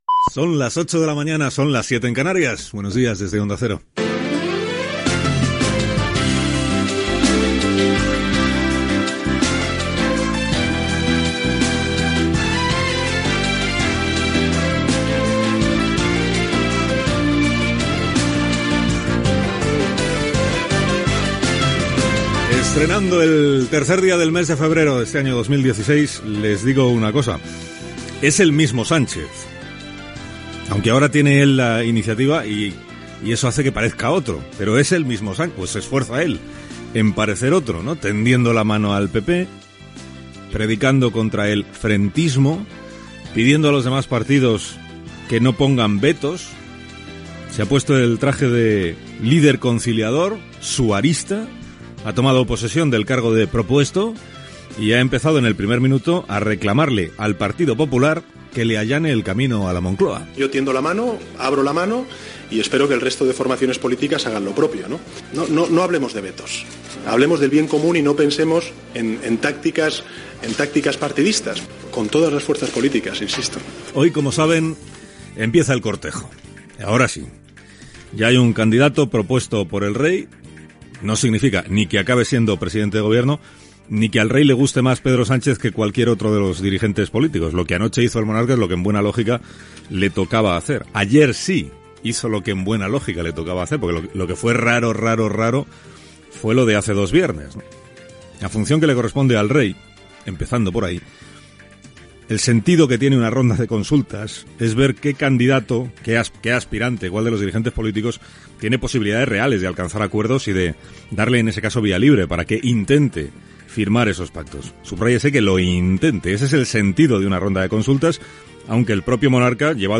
Hora, monòleg de Carlos Alsina després de la ronda de contactes del Rei amb els partits polítics per presentar la candidatura de Pedro Sánchez a president del Govern espanyol
Info-entreteniment
FM